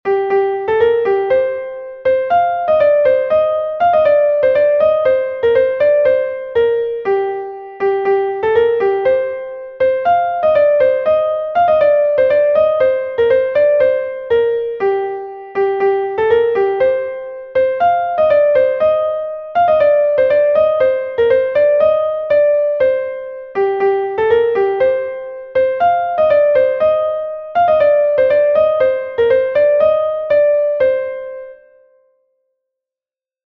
Ton Bale Bubri is a Bale from Brittany